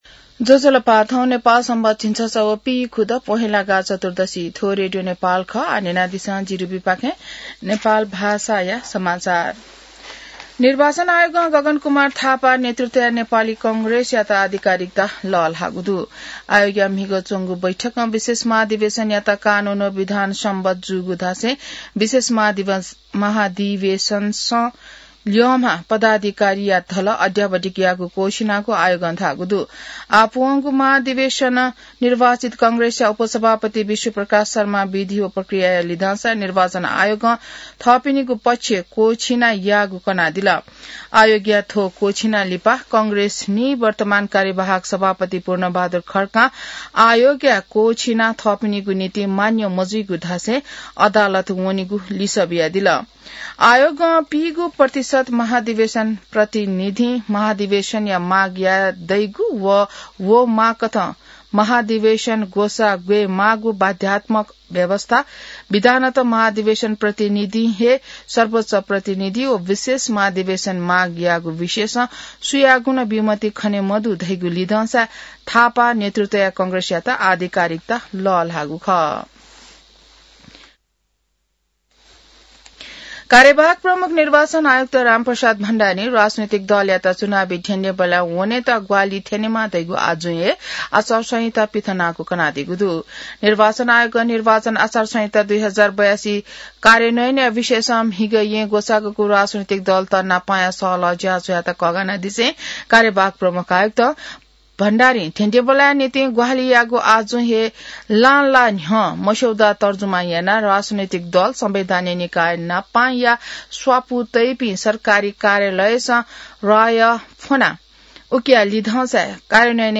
नेपाल भाषामा समाचार : ३ माघ , २०८२